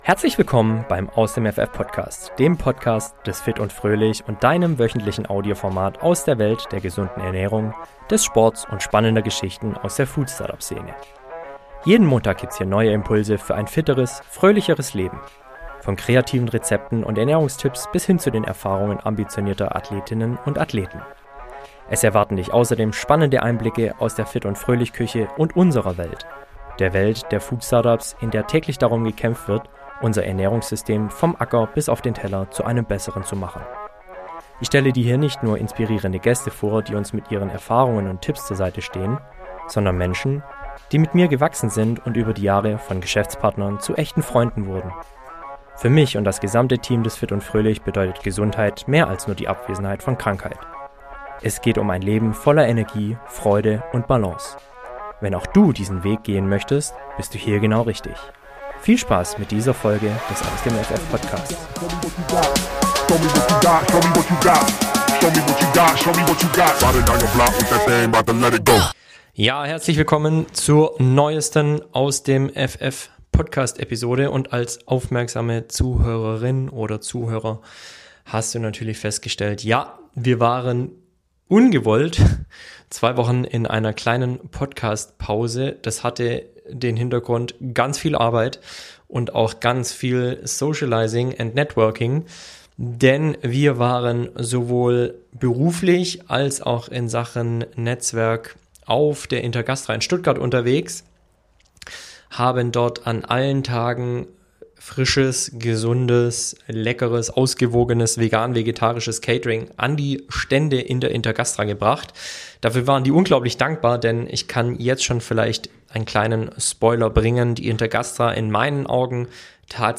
In dieser Folge nehmen wir Dich live mit auf zwei der spannendsten Food-Messen Deutschlands: Intergastra in Stuttgart und Biofach in Nürnberg.
Begleite uns auf diesem Streifzug voller Energie, ehrlicher Gespräche und überraschender Food-Trends – direkt aus dem Herzen der Messehallen.